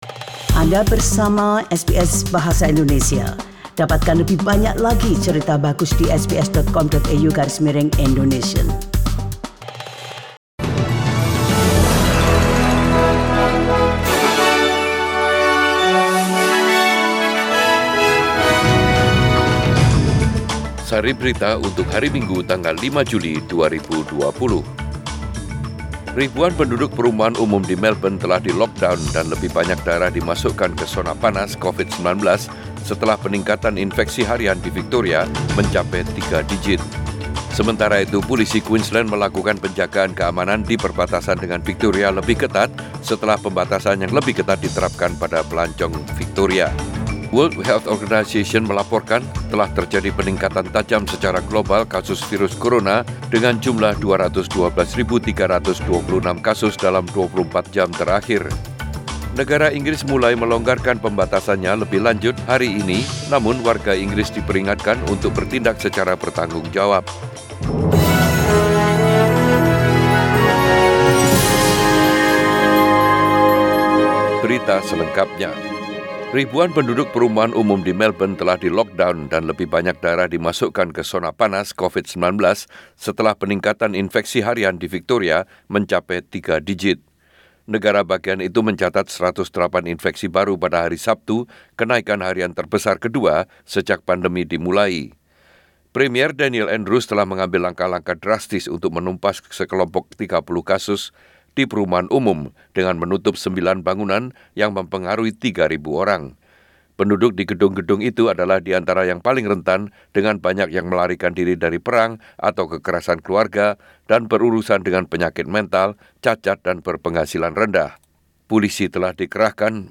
SBS Radio News in Bahasa Indonesia - 7 July 2020